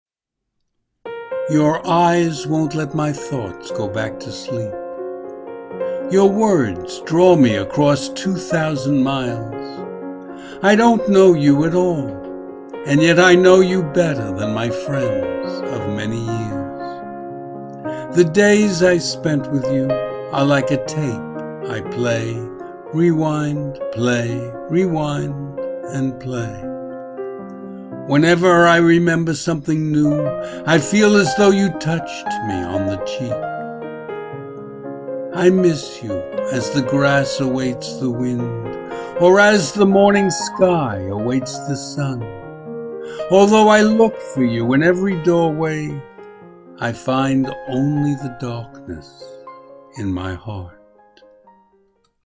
Audio and Video Music: